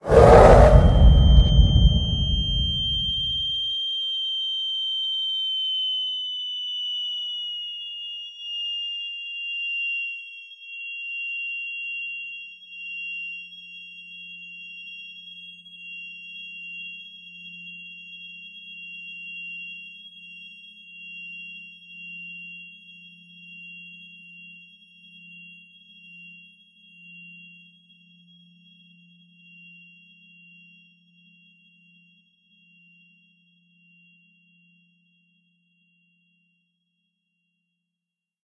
spearYellRumbleTinnitusProlongVoice.ogg